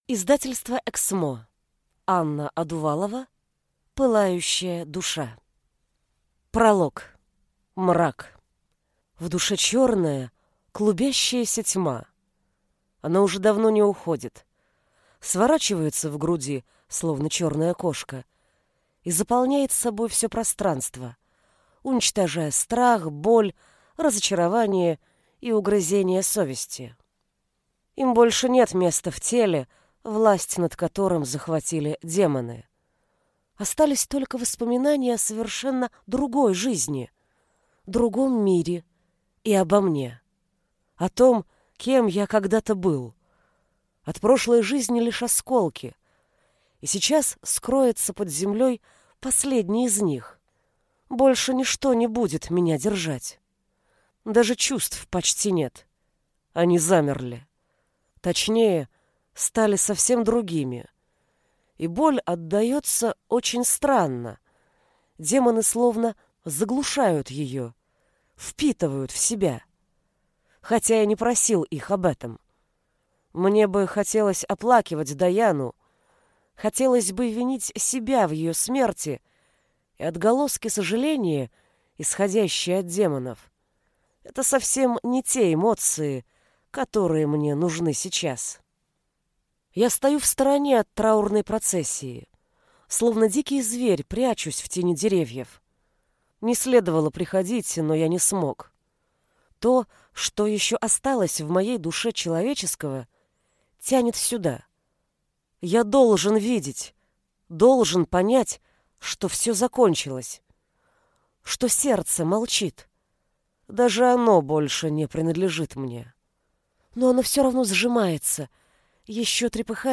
Аудиокнига Пылающая душа | Библиотека аудиокниг